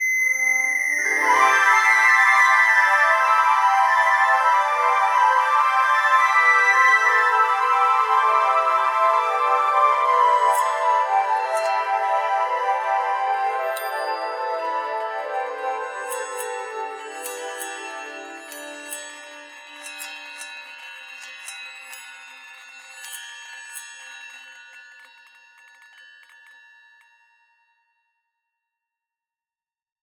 Data from NASA’s James Webb Space Telescope is heard as metallic bell-like sounds, while the light of the central star is mapped to produce the descending scream-like sound at the beginning.